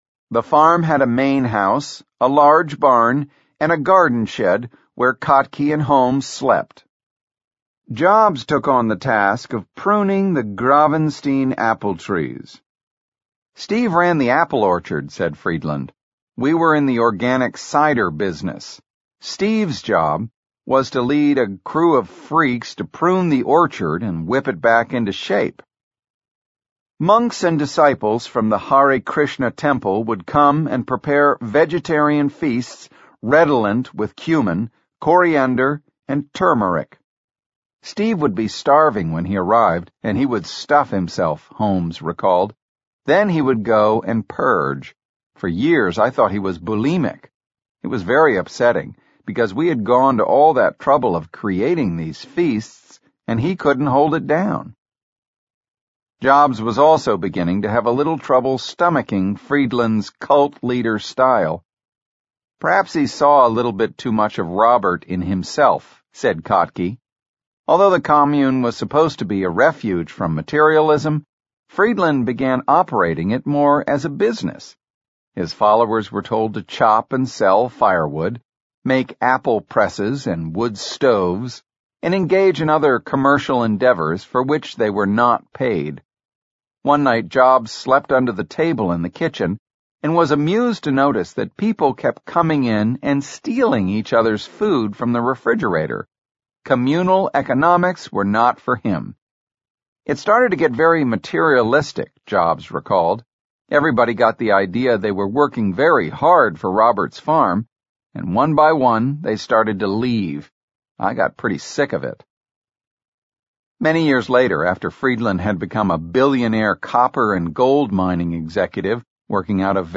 在线英语听力室乔布斯传 第36期:弗里德兰(3)的听力文件下载,《乔布斯传》双语有声读物栏目，通过英语音频MP3和中英双语字幕，来帮助英语学习者提高英语听说能力。
本栏目纯正的英语发音，以及完整的传记内容，详细描述了乔布斯的一生，是学习英语的必备材料。